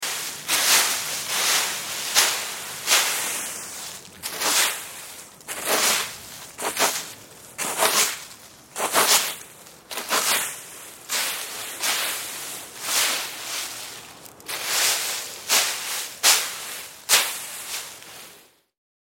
Звуки граблей
На этой странице собраны реалистичные звуки граблей – от мягкого шелеста листьев до характерного скрежета по земле.
Уборка сухой листвы граблями на своем заднем дворе